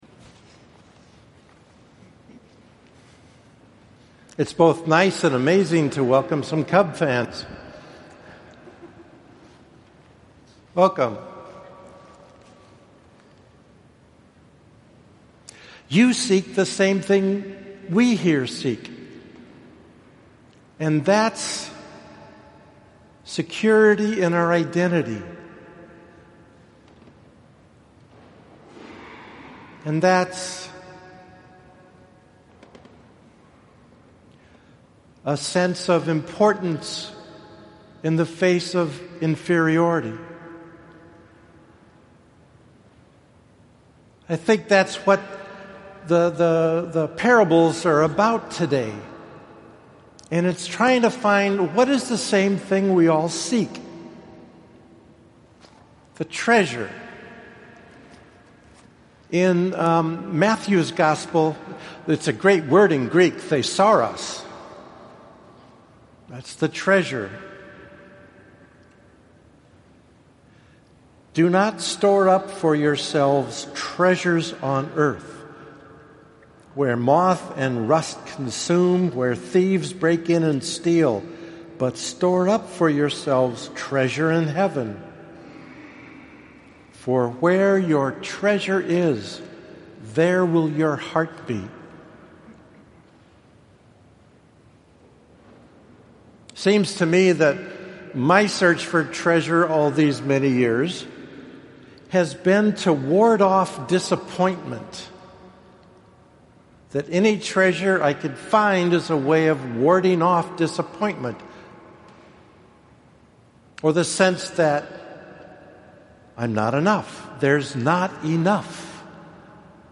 Here is the audio homily plus a couple observations…